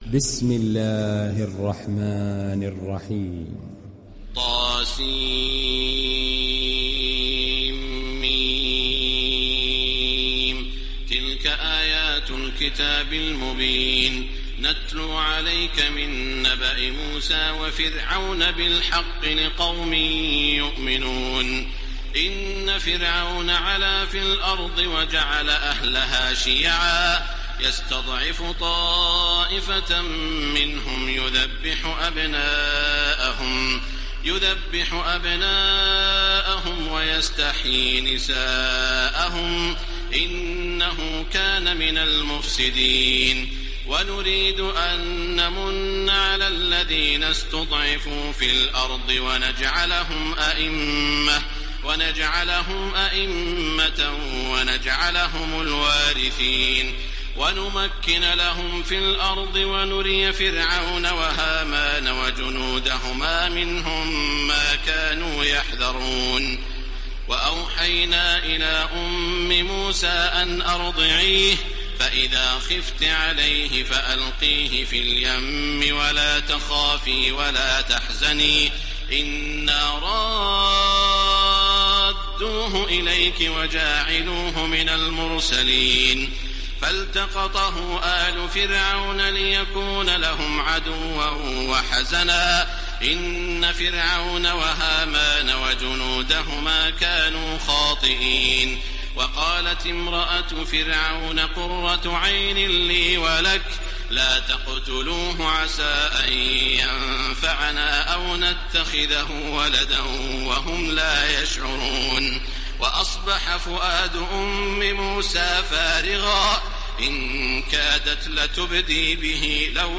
Surat Al Qasas mp3 Download Taraweeh Makkah 1430 (Riwayat Hafs)
Download Surat Al Qasas Taraweeh Makkah 1430